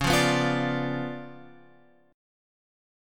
C#7b9 chord {9 8 9 10 x 10} chord